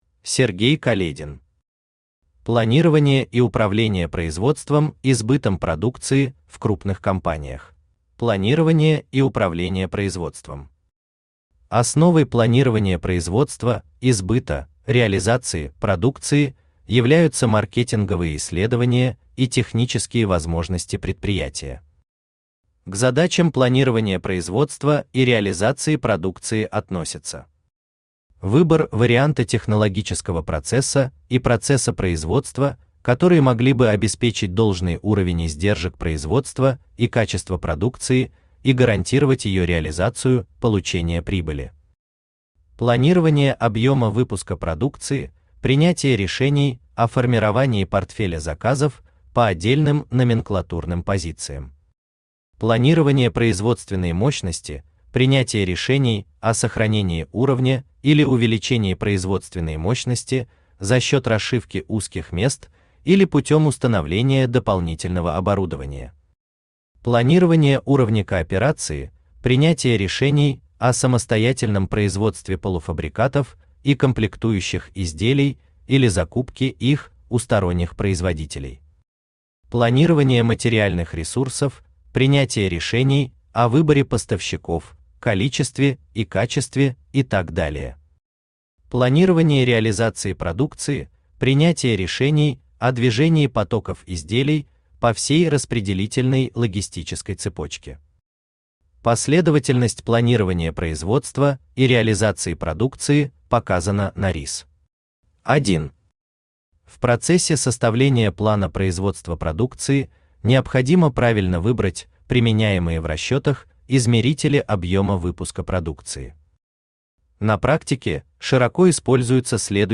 Aудиокнига Планирование и управление производством и сбытом продукции в крупных компаниях Автор Сергей Каледин Читает аудиокнигу Авточтец ЛитРес.